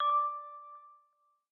click-correct.m4a